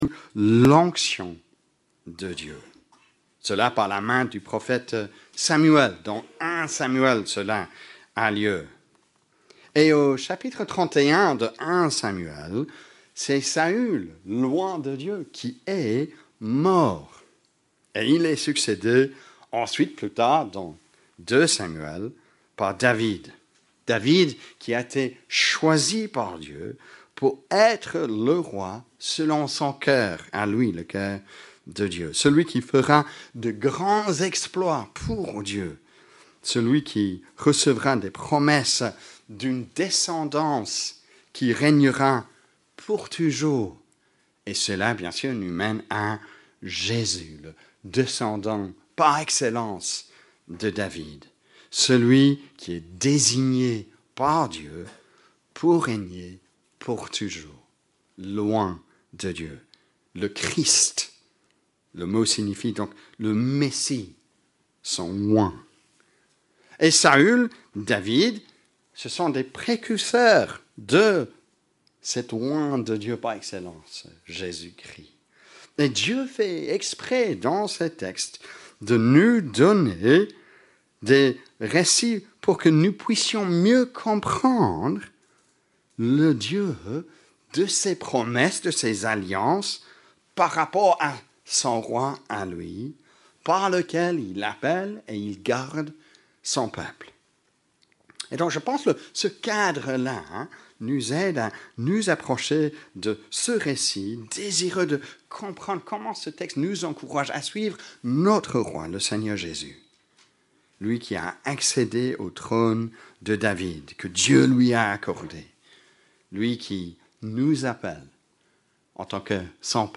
Predication-22-01.mp3